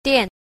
8. 墊 – diàn – điếm (trải, kê, đệm)